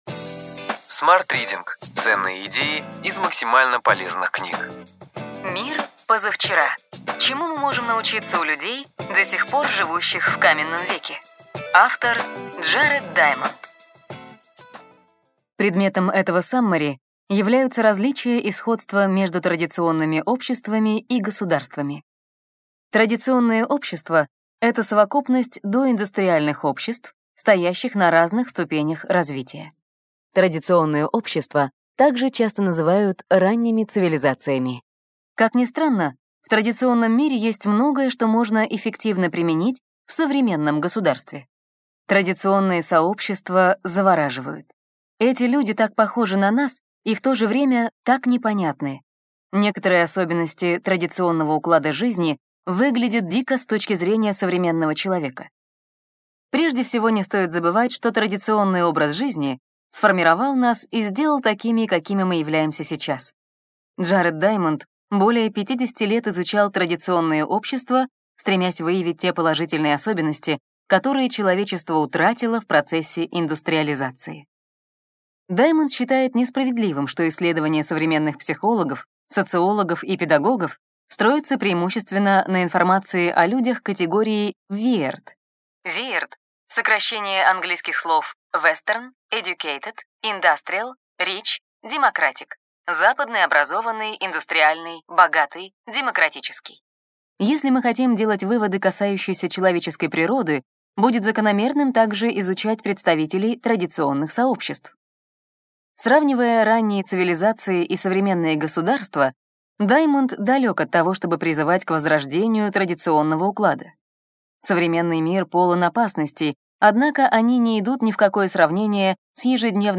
Аудиокнига Ключевые идеи книги: Мир позавчера. Чему мы можем научиться у людей, до сих пор живущих в каменном веке. Джаред Даймонд | Библиотека аудиокниг